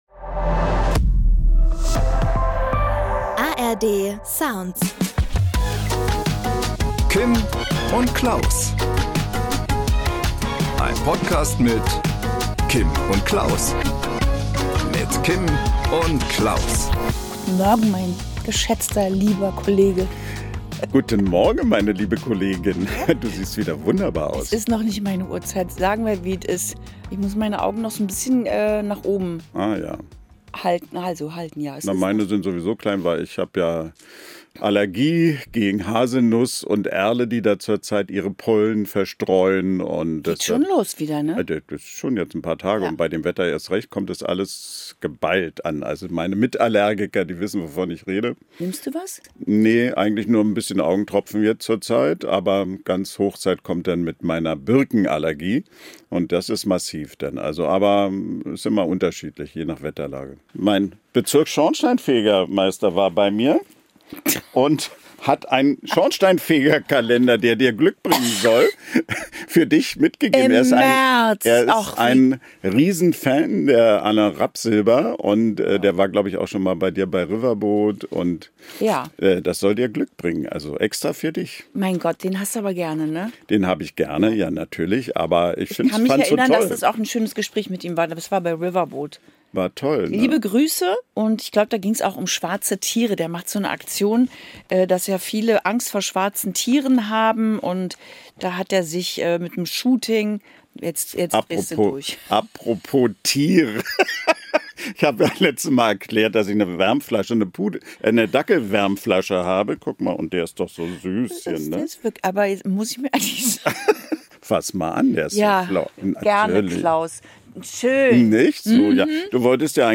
Am Promitelefon: Volksmusik-Moderator Maximilian Arland.